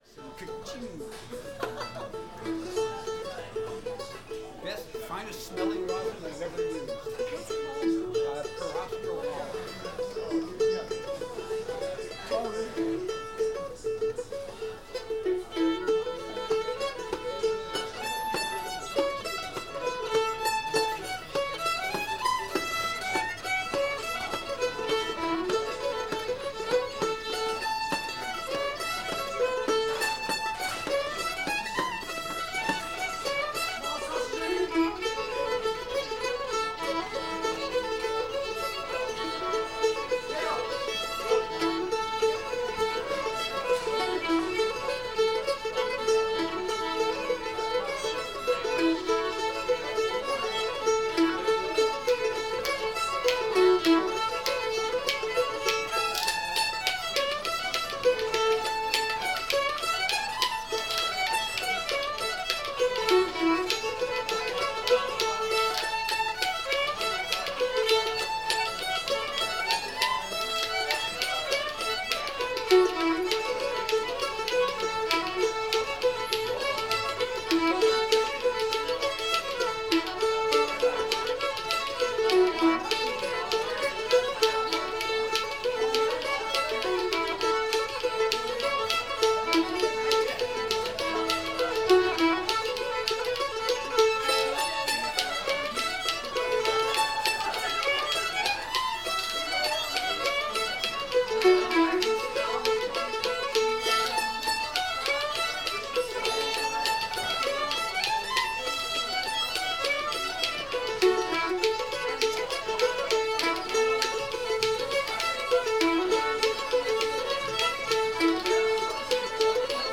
tar river [A]